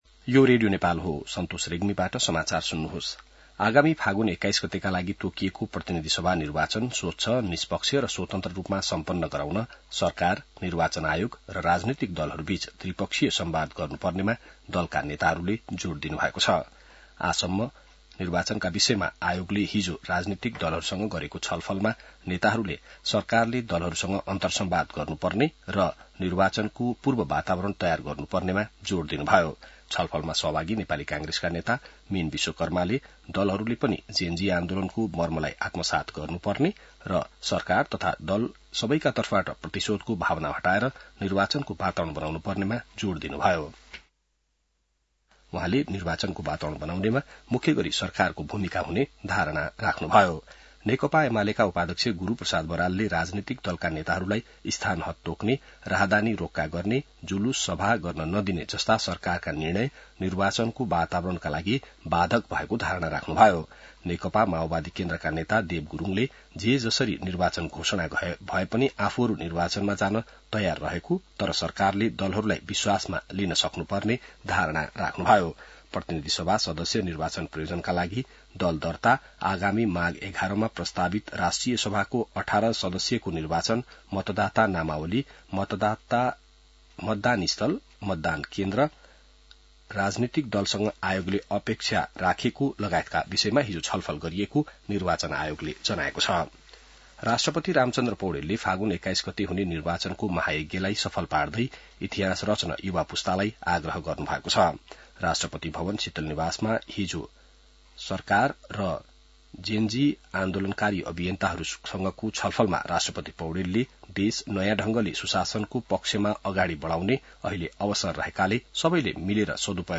बिहान ६ बजेको नेपाली समाचार : ३१ असोज , २०८२